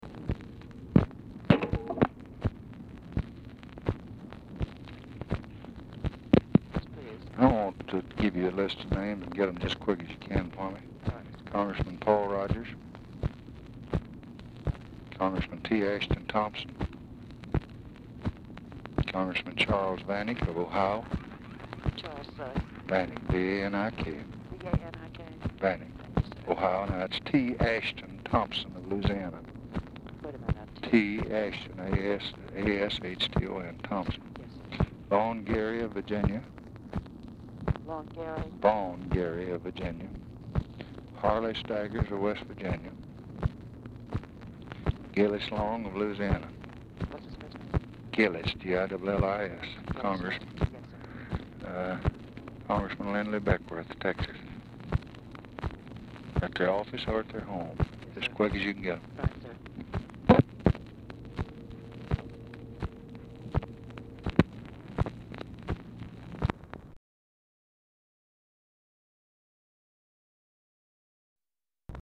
Telephone conversation # 2885, sound recording, LBJ and TELEPHONE OPERATOR, 4/7/1964, time unknown | Discover LBJ
Format Dictation belt
White House Telephone Recordings and Transcripts Speaker 1 LBJ Speaker 2 TELEPHONE OPERATOR